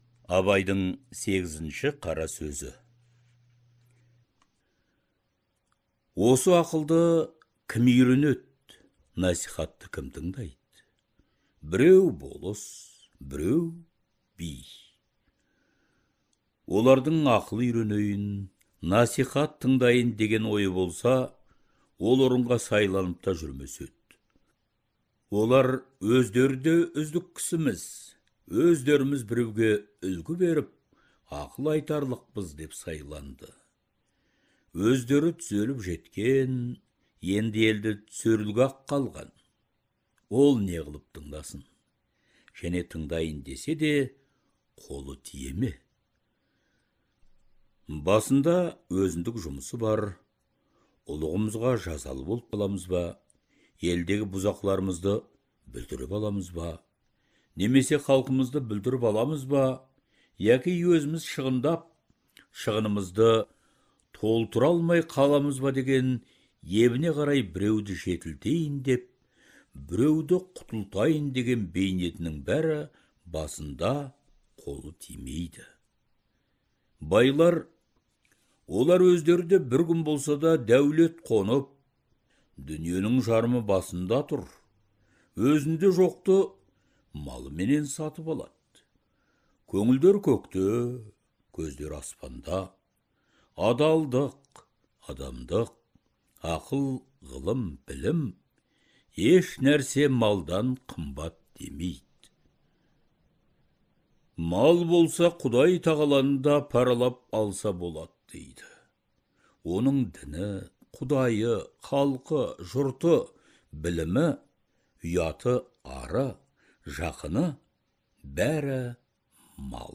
Даналық көсемсөздердің аудио нұсқасы Әдебиет институтының студиясында жазылып алынған.